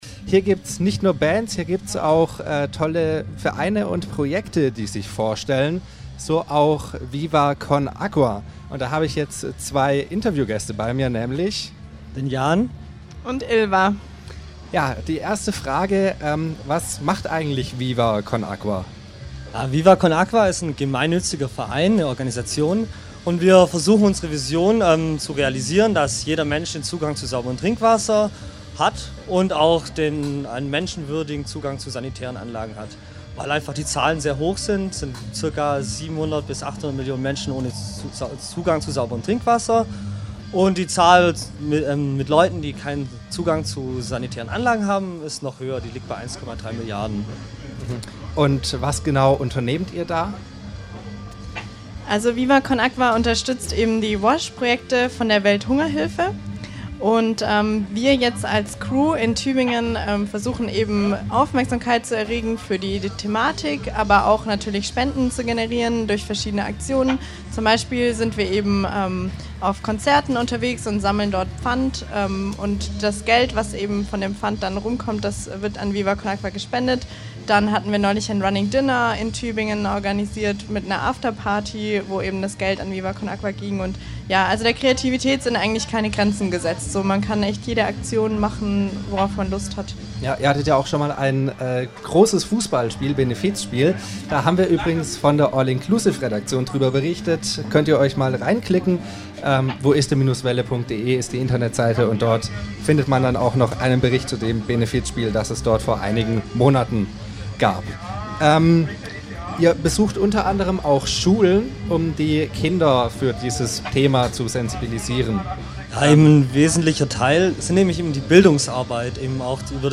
Die Wüste Welle war live beim Ract!fetival im Tübinger Anlagenpark dabei.
Interview mit Viva con Agua
58734_Interview_Viva_con_Agua.mp3